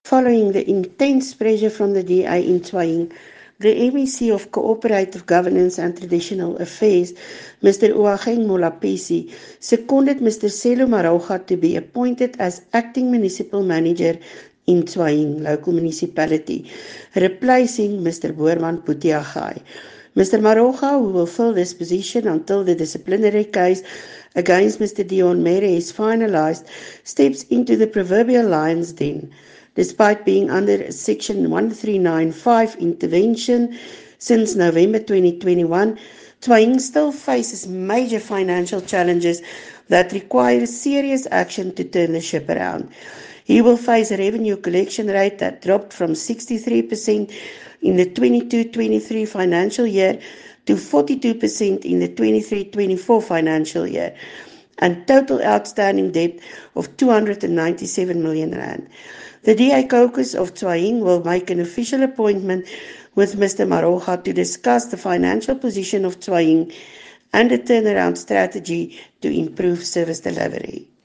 Note to broadcasters: Please find linked soundbites in
English and Afrikaans by cllr Soret Viljoen